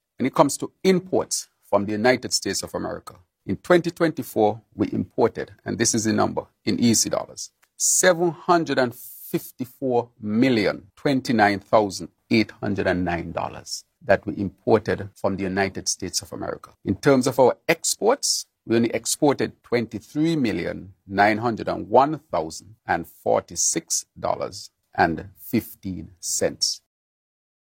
That was Prime Minister and Minister of Finance, the Hon. Dr. Terrance Drew.